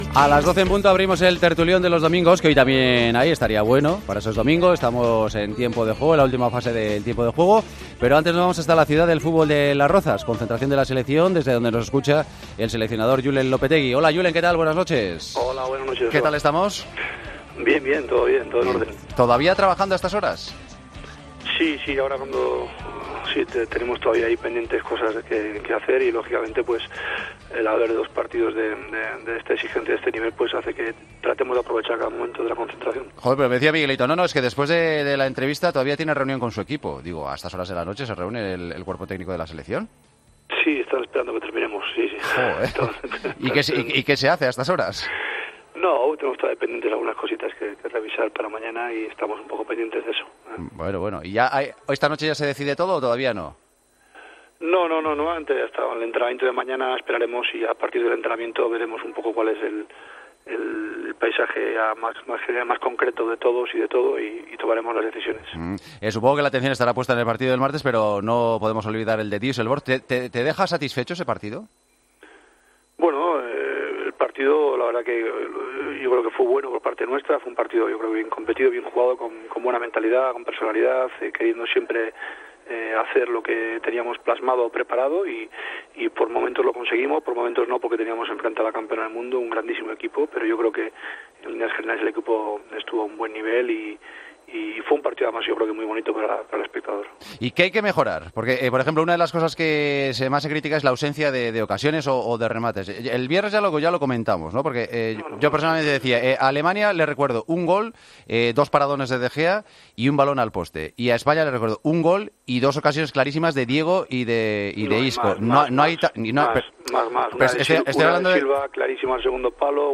Hablamos con el seleccionador nacional de cara al amistoso ante Argentina de este martes.